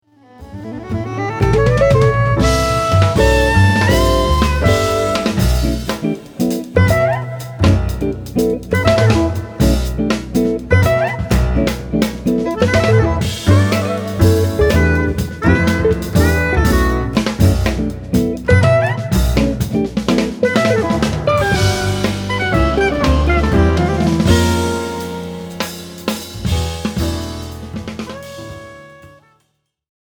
From my jazz debut album